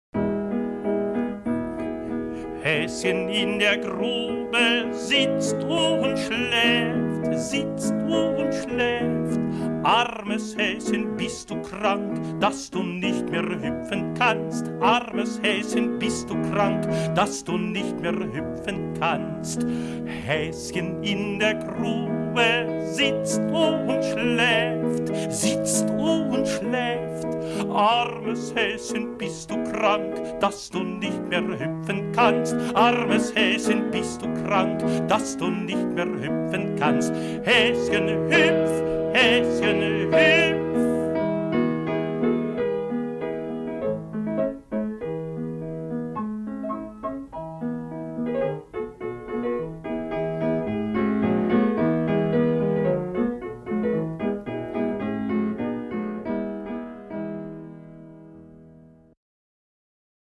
Kinder- und Spiellied